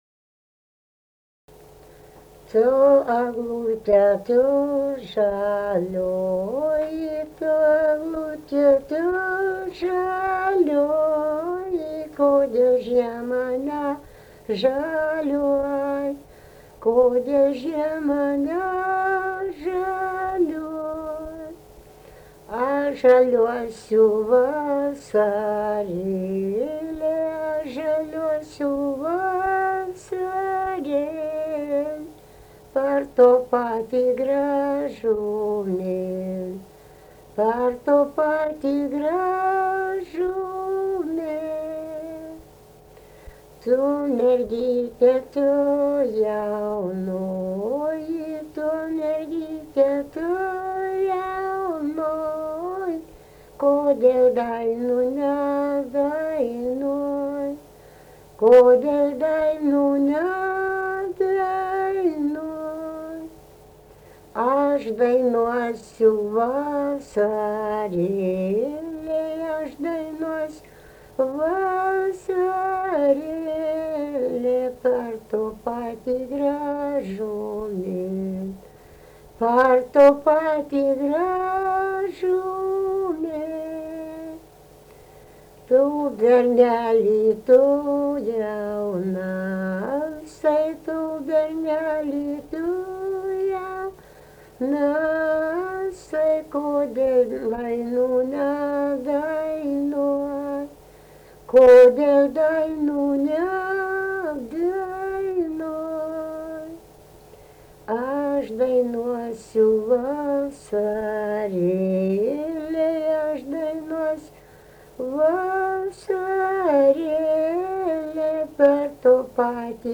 daina
vokalinis